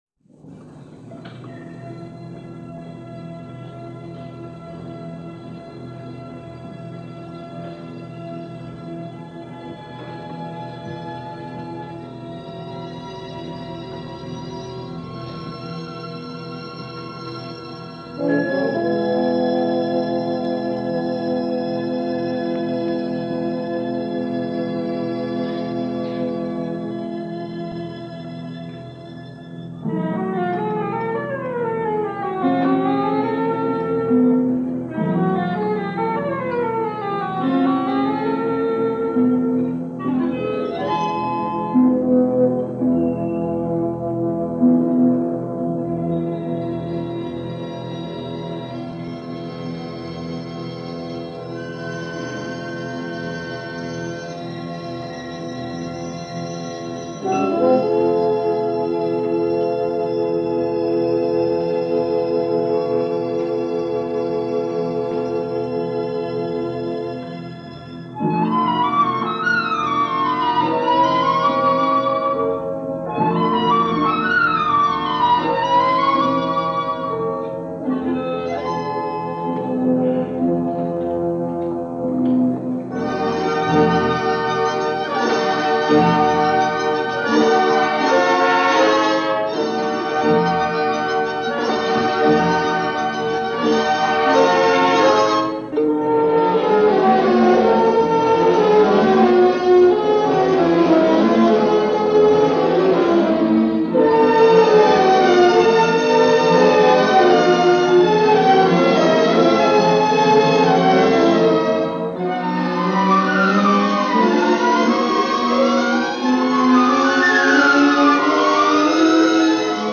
Andrè Cluytens Leads The Paris Conservatory Orchestra In Music Of Dukas - Besancon Festival 1951 - Past Daily Weekend Gramophone
The Paris Conservatory Orchestra, lead by the legendary Andrè Cluytens at the 1951 Besancon Festival in a performance of La Peri by Paul Dukas.